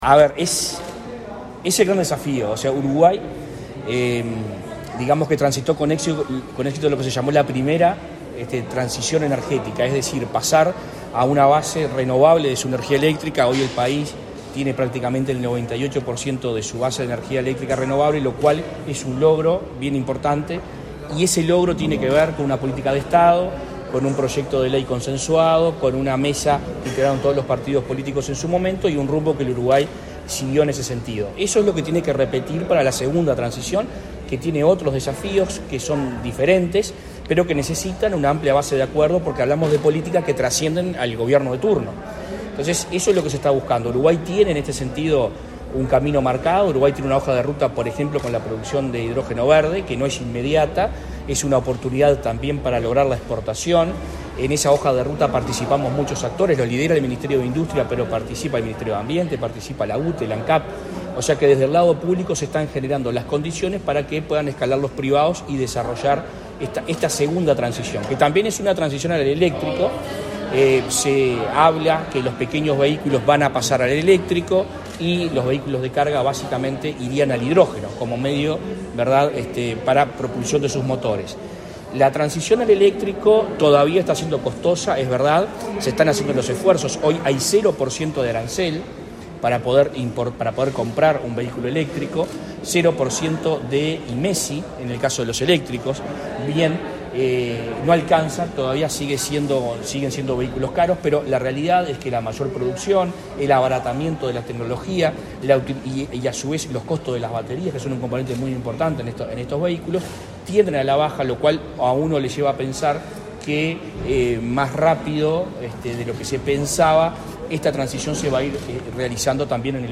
Declaraciones a la prensa del ministro de Ambiente
Luego, Peña dialogó con la prensa.